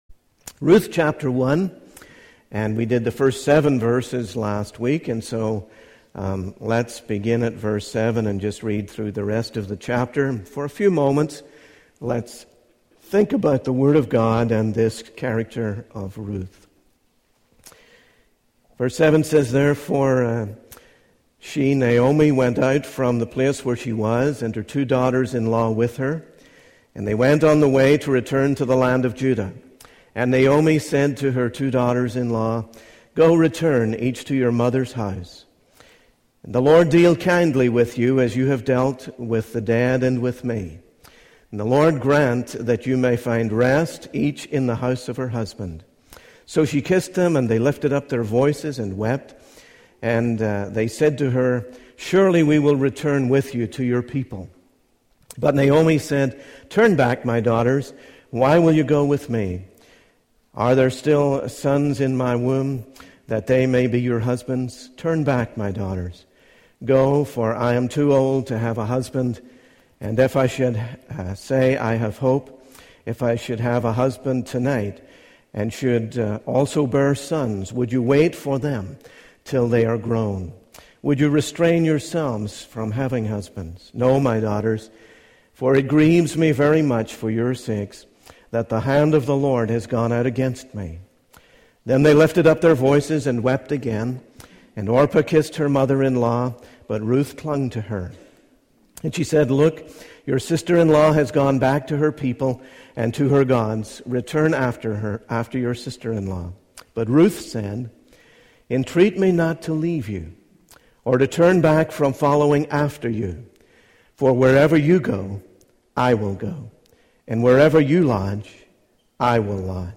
Naomi's decision to return to Judah reflects a longing for restoration despite her bitterness, while Ruth's unwavering commitment to Naomi and her God showcases true conversion and loyalty. In contrast, Orpah's choice to return to Moab symbolizes a superficial commitment that lacks depth and transformation. The sermon highlights the importance of making life-altering decisions that align with God's will and the welcoming nature of the people of God.